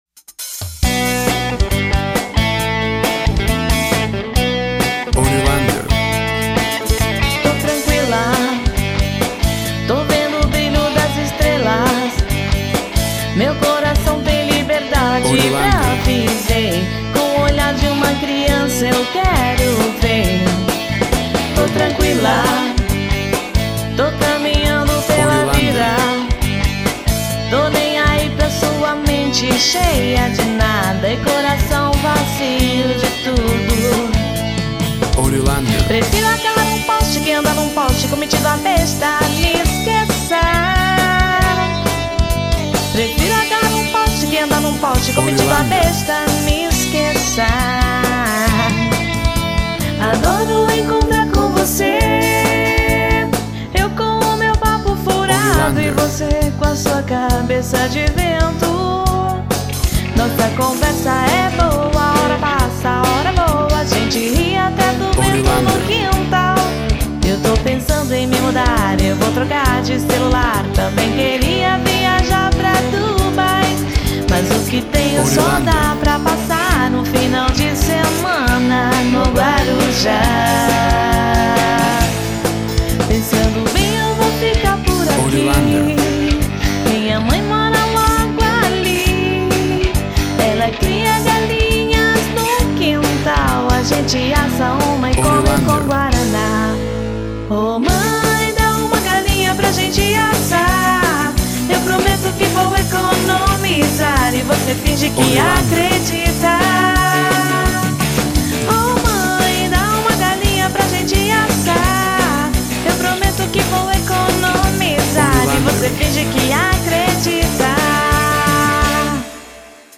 Rock pop music.
Tempo (BPM) 136